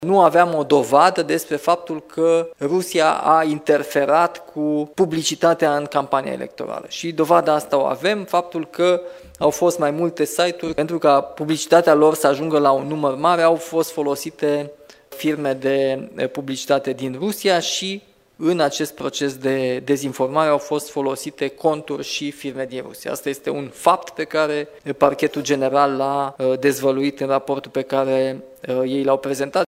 Prezent la Timișoara, președintele Nicușor Dan a spus că, față de noiembrie 2024, există mai multe elemente de context și specifice referitoare la motivele anulării alegerilor prezidențiale.